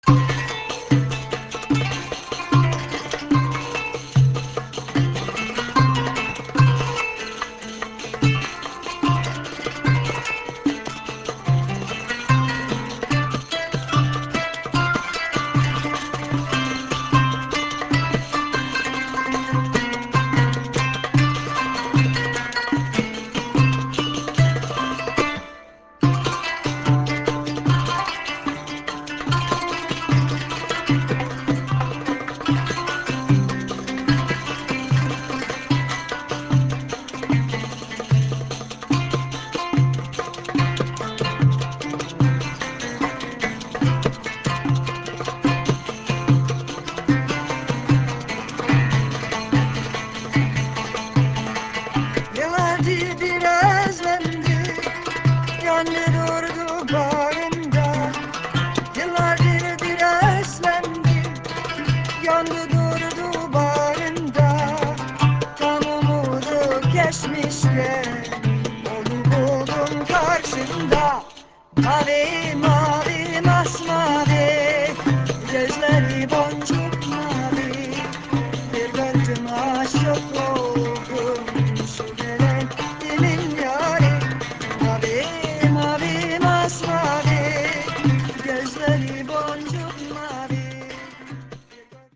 qanun (zither)
doumbec and tambourine